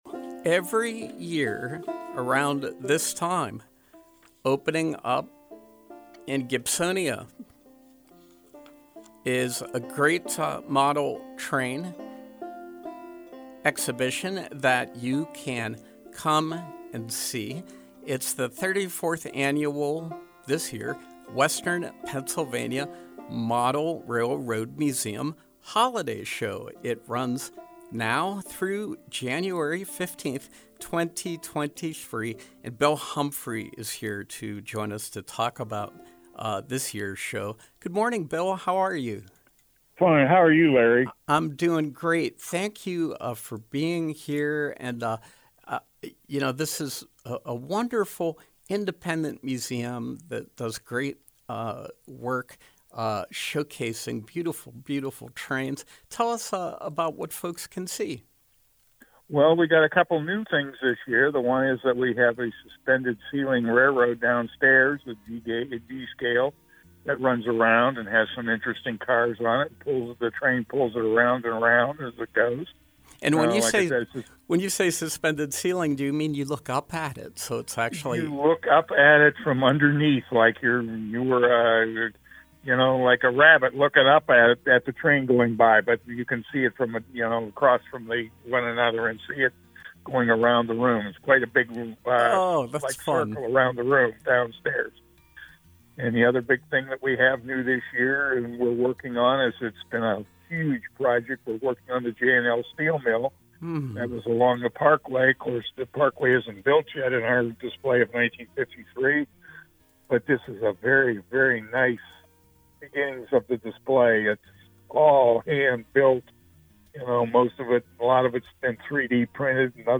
Interview: Western Pa. Model Railroad Museum